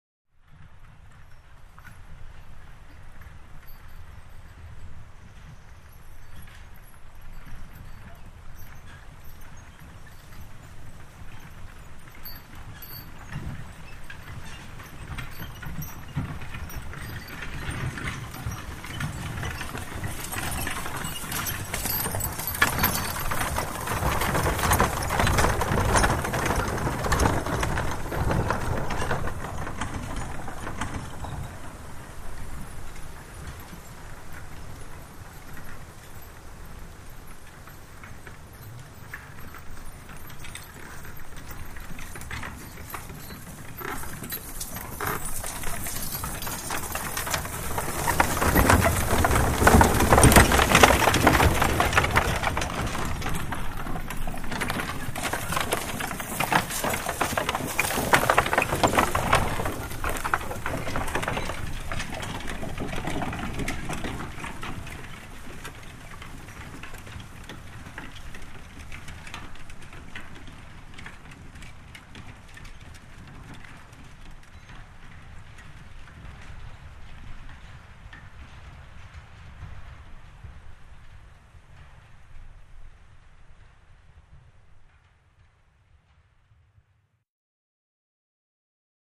3 Horse Drawn Wagons; By Slow, Grass Surface, L-r, With Light Background Wind Through Trees